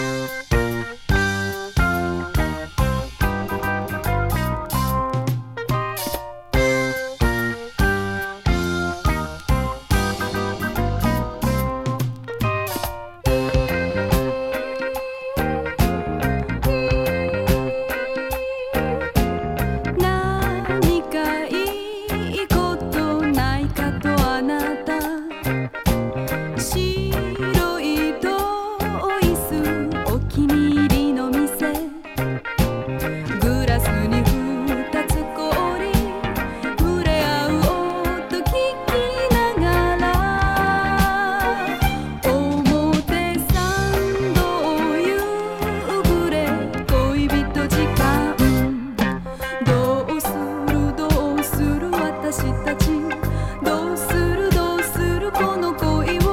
ベースも太いです。